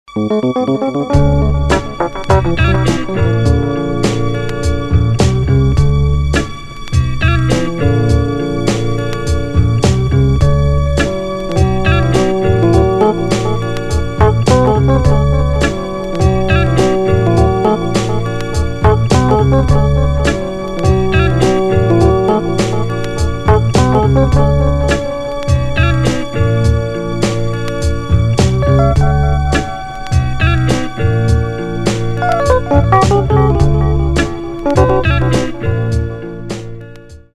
красивые
спокойные
без слов
успокаивающие
спокойная музыка
Стиль: Nu Jazz